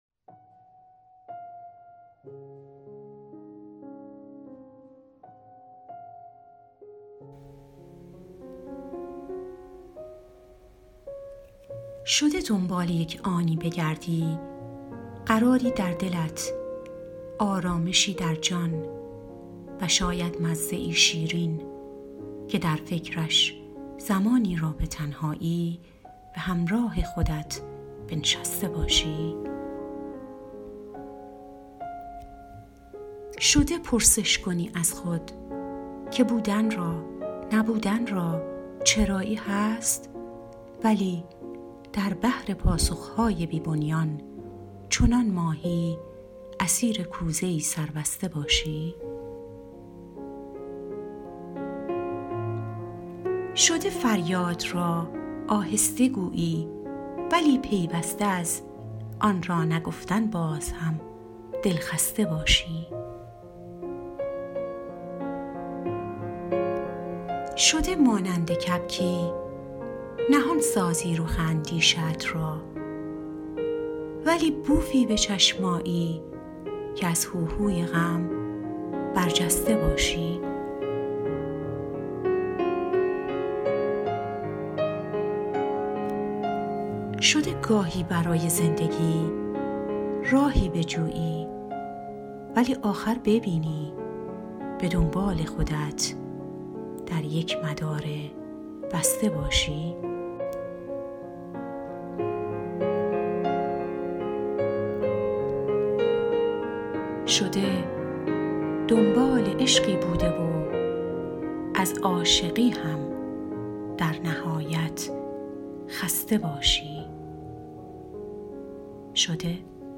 خوانش شعر شعر و خوانش